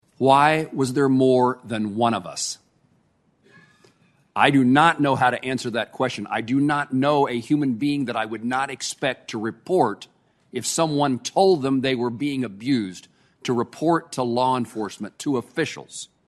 U.S. Senator Jerry Moran offered the opening statements Wednesday morning as the Senate Judiciary dealt with the FBI’s handling of the sexual assaults of multiple gymnasts by former Olympic and Michigan State doctor Larry Nassar.